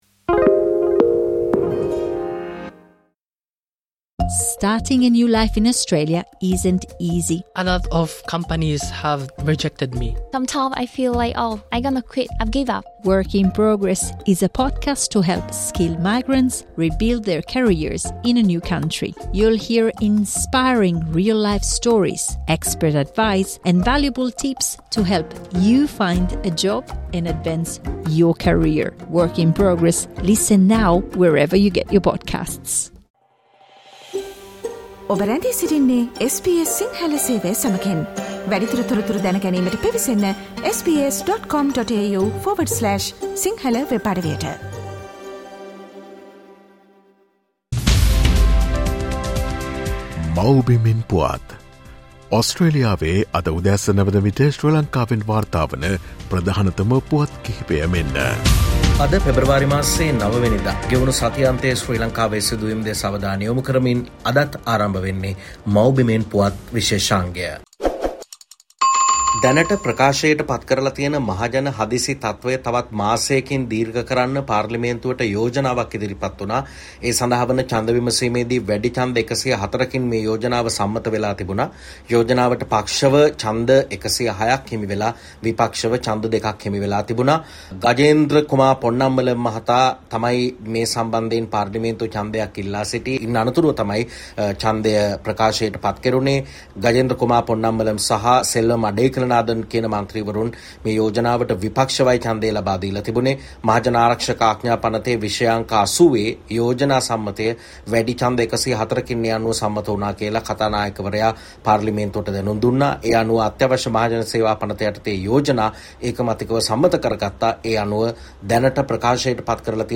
ගෙවුණු සතිඅන්තයේ ශ්‍රී ලංකාවෙන් වාර්තා වූ උණුසුම් හා වැදගත් පුවත් සම්පිණ්ඩනය.